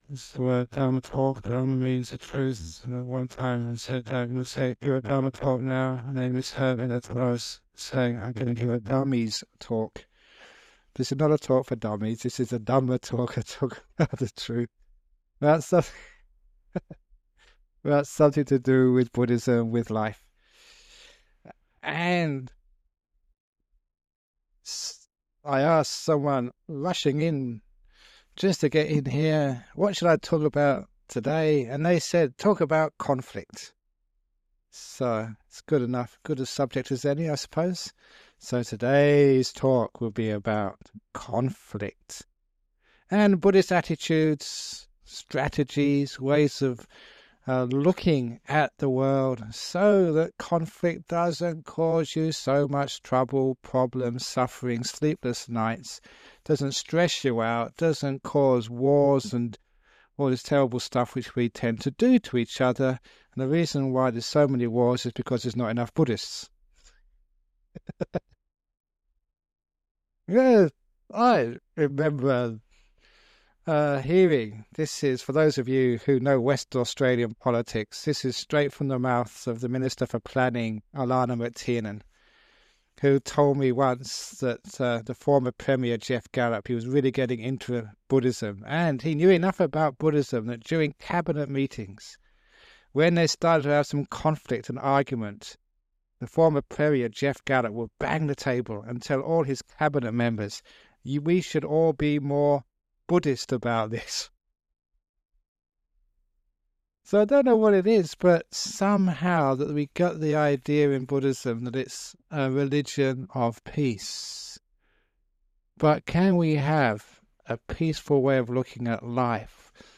Ajahn Brahm talks about the Buddhist conception of emptiness and how this relates to modern life and meditation.—This dhamma talk was originally recorded using a low quality MP3 to save on file size on 9th December 2005.